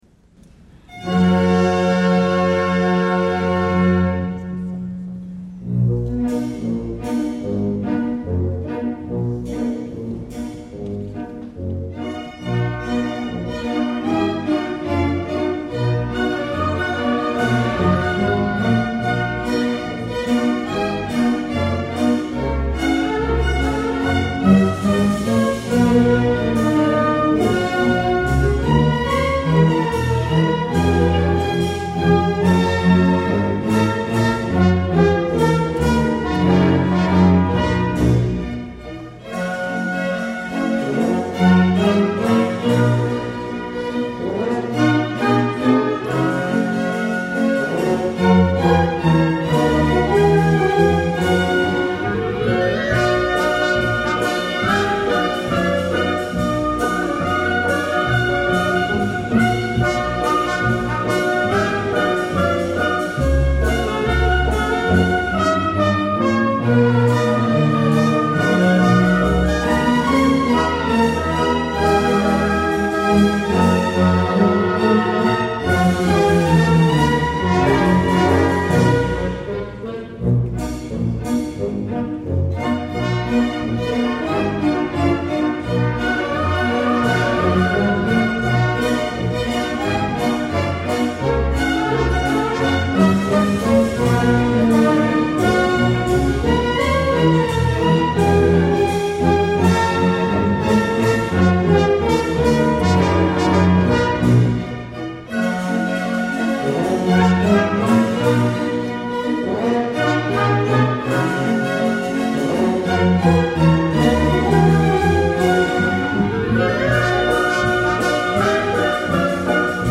There is a Tavern (traditional) – διασκευή για Ορχήστρα